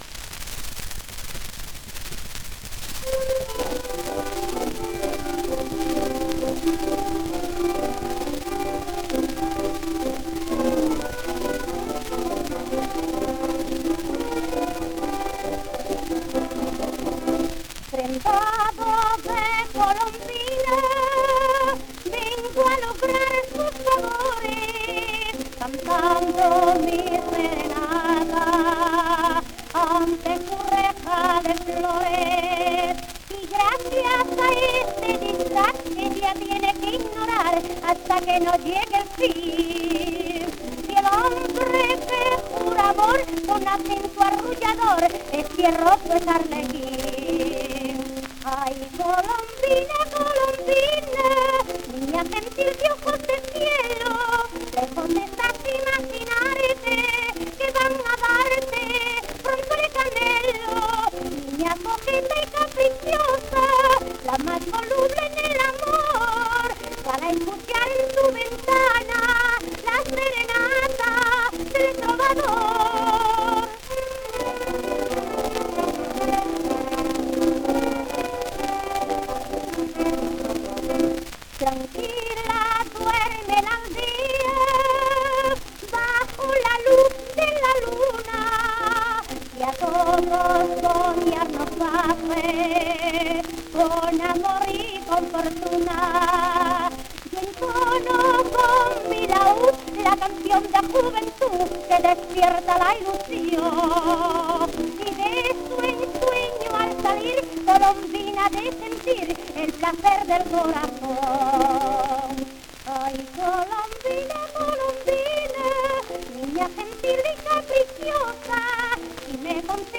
1 disco : 78 rpm ; 25 cm Intérprete
acompañamiento de orquesta